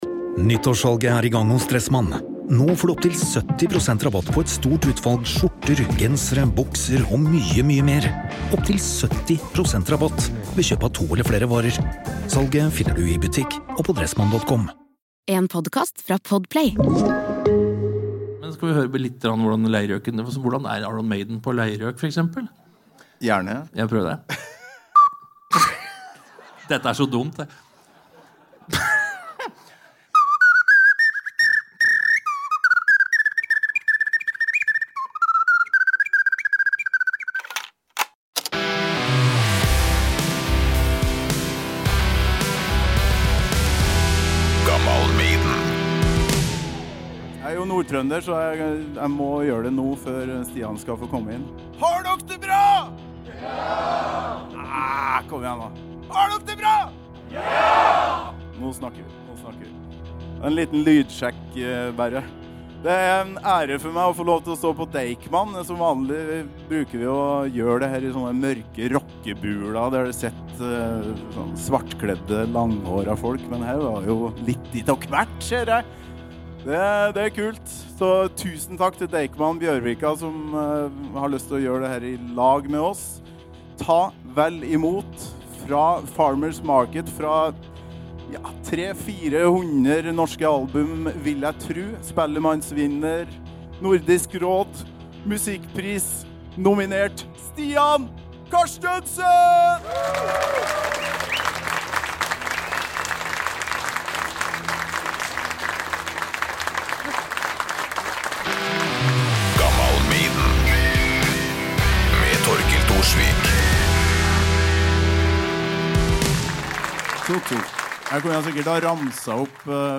Beschreibung vor 1 Jahr For en kveld! Stian Carstensen foran 150 publikummere i et av landets vakreste bygg, Deichman Bjørvika. Sekkepipe, leirgjøk, fløyte, trekkspill, sang, latter, Maiden, "Hethro Tull" og den gode samtale.